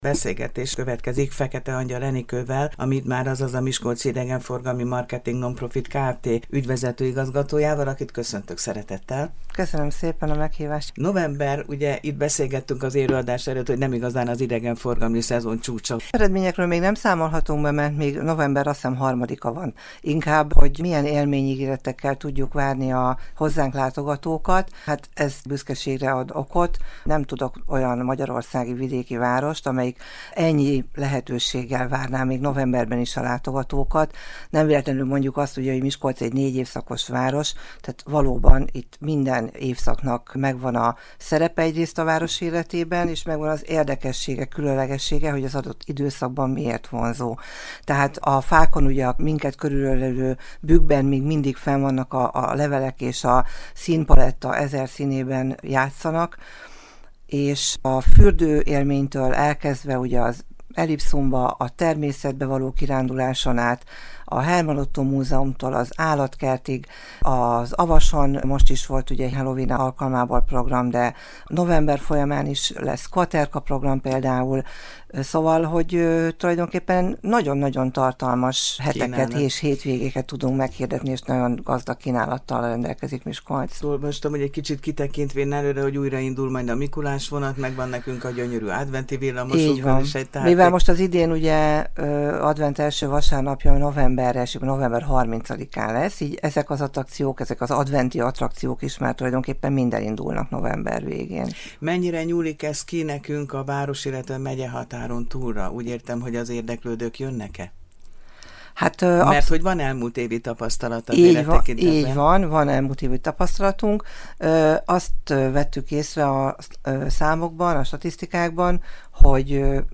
Közben pedig büszkén sorolja a Csillagpont Rádió műsorában azokat a látni- és megmutatnivalókat, amelyekkel Miskolc egyedüliként rendelkezik az országban.